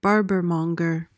PRONUNCIATION:
(BAR-buhr-mong-uhr)